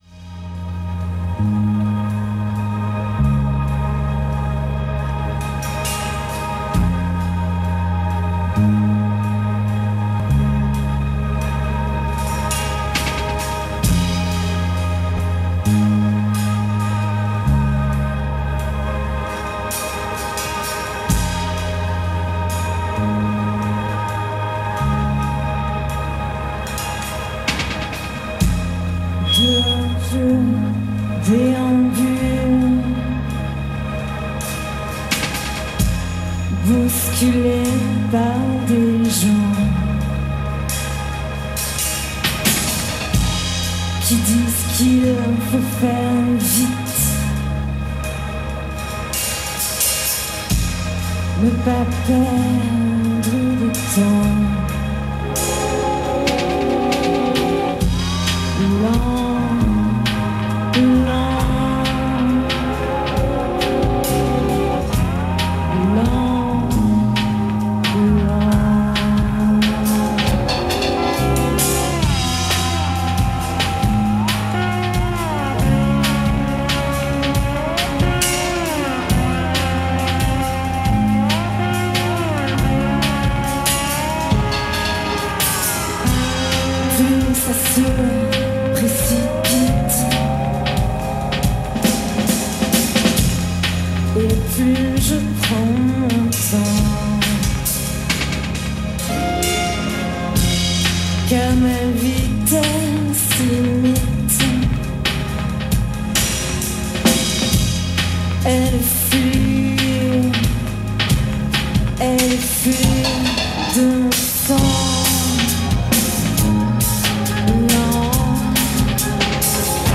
in concert from the Benicasim Festival
vocals
leaning heavily on electronics and soundscapes
Ethereal from the word “go”.